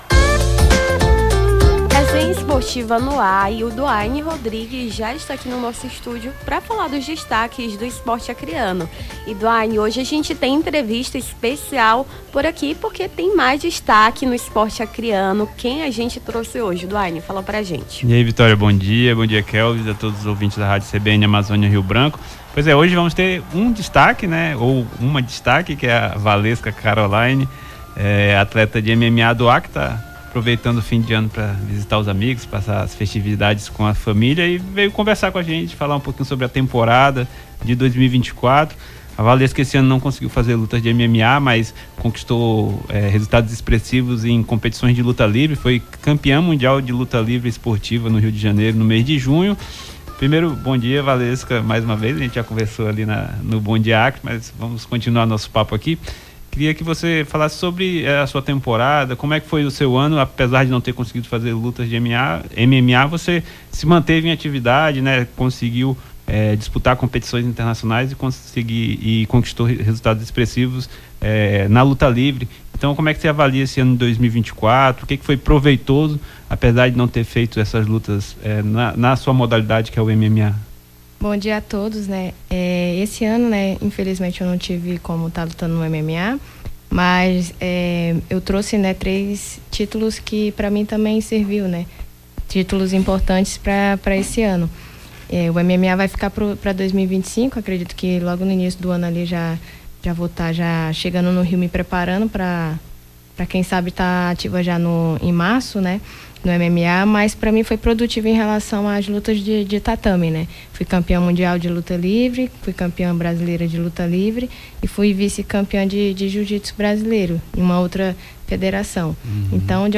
Resenha Esportiva: lutadora acreana fala sobre planos para o futuro